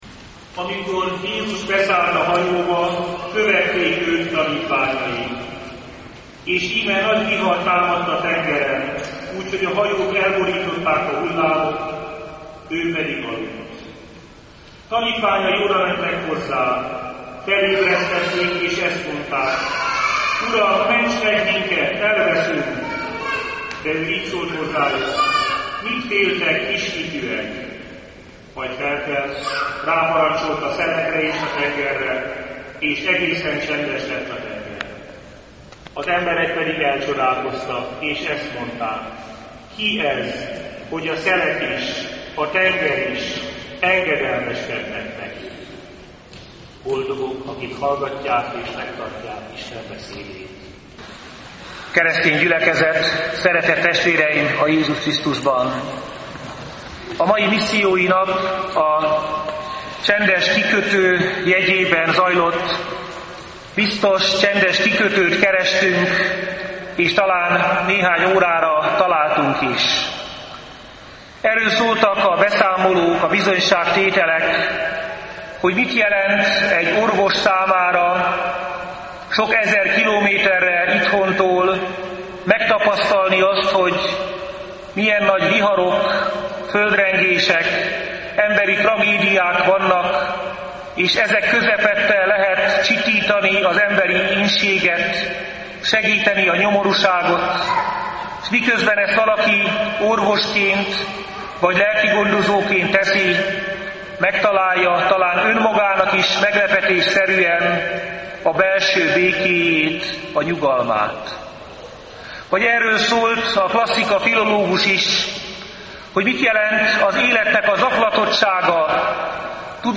Salgótarján – 2010. május 15-én az Északi Evangélikus Egyházkerület Missziói Napján készült felvételeket közöljük.